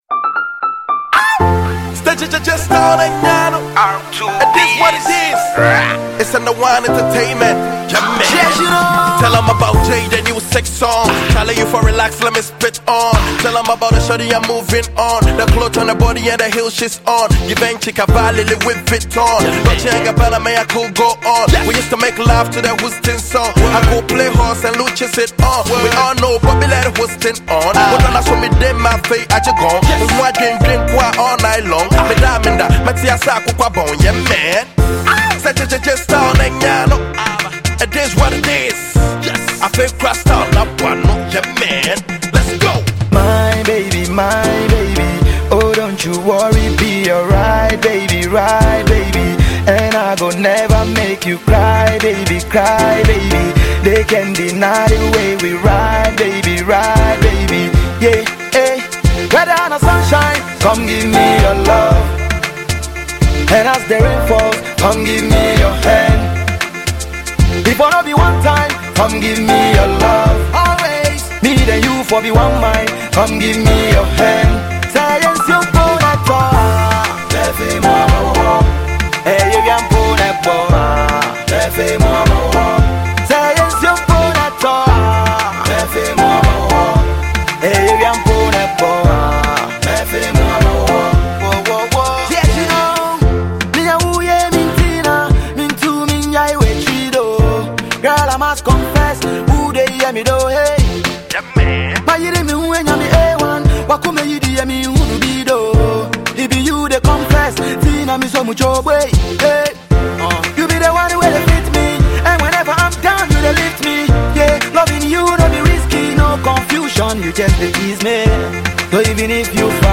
catchy love song